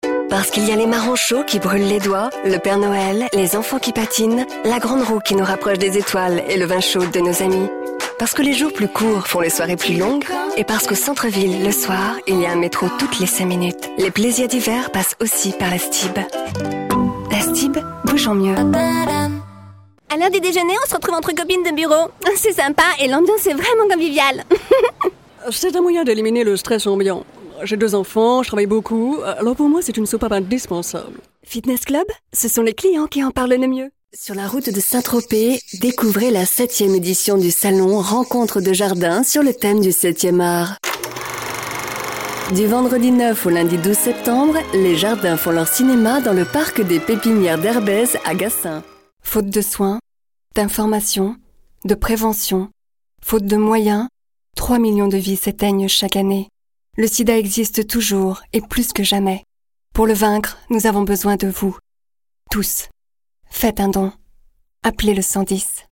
Mon timbre de voix médium (hertzien) qui me permet de passer d'un ton du plus institutionnel, à un ton classe, souriant, sérieux, rassurant,sensuel convaincant, chaleureux, doux, ravissante idiote...jusqu'aux voix jouées!
Sprechprobe: Werbung (Muttersprache):
My stamp of voice medium which allows me to pass of a tone of the most institutional, in a classy, smiling, serious, reassuring, convincing, warm, soft tone, charming stupid until the played voices!